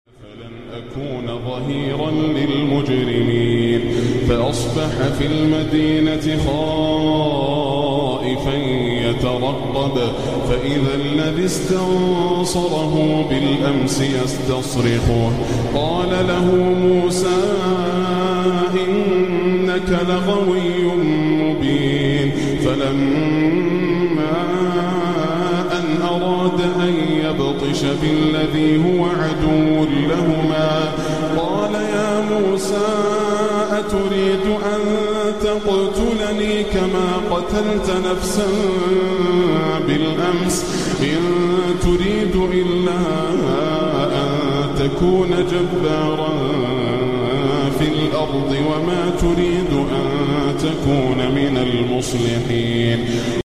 Wear your headphones You will feel like you are in Masjid al-Haram 8D Audio 8D quran